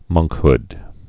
(mŭngkhd)